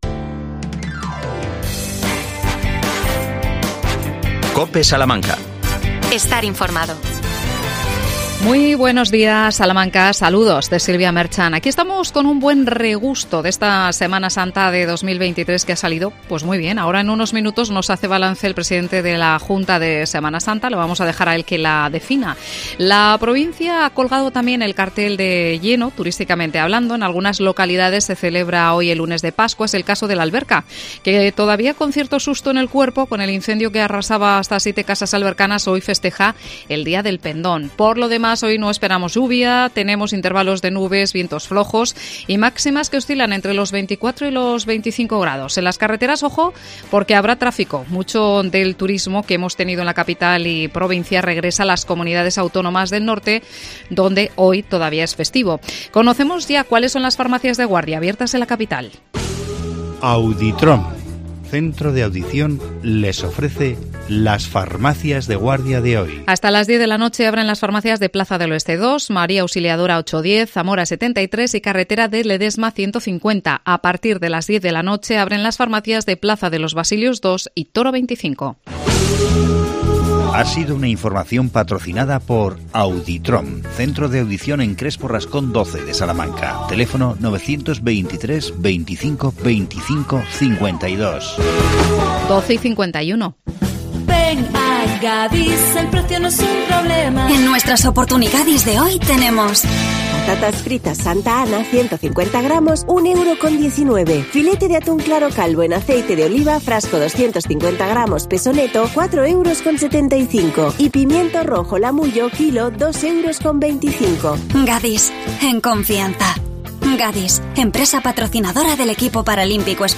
Balance de la Semana Santa de Salamanca 2023. Entrevistamos